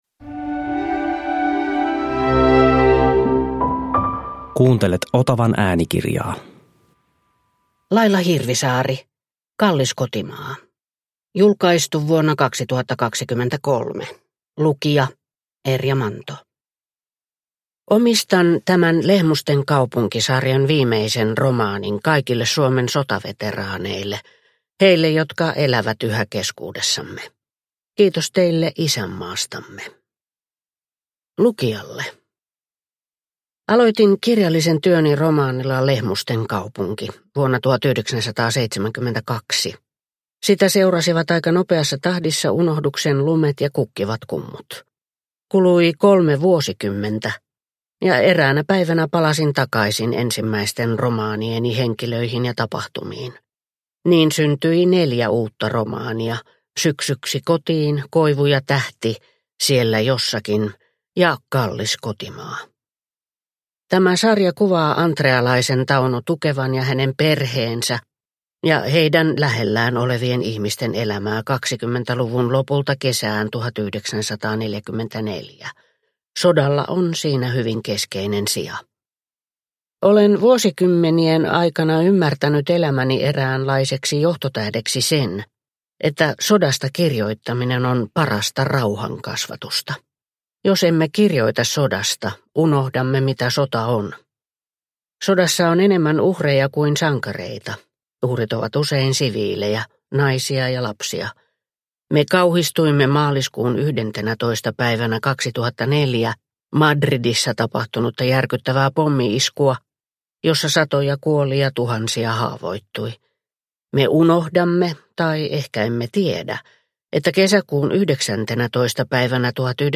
Kallis kotimaa – Ljudbok – Laddas ner